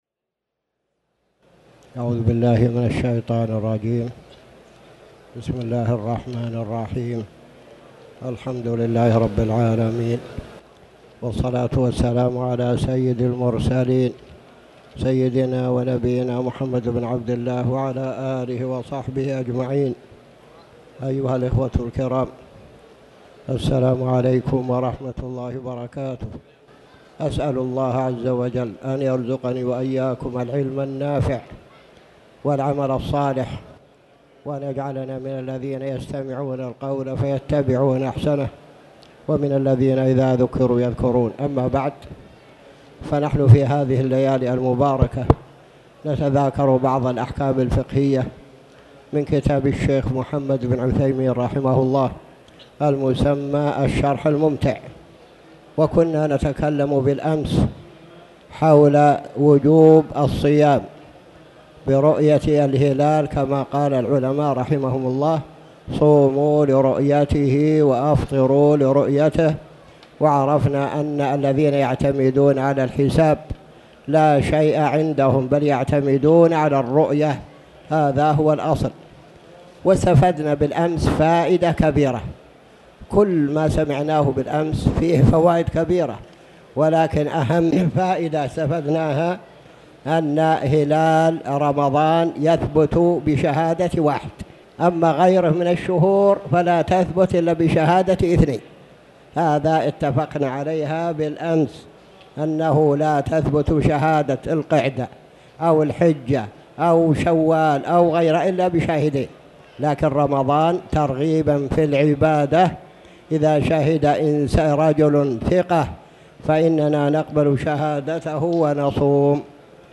تاريخ النشر ١٢ جمادى الأولى ١٤٣٩ هـ المكان: المسجد الحرام الشيخ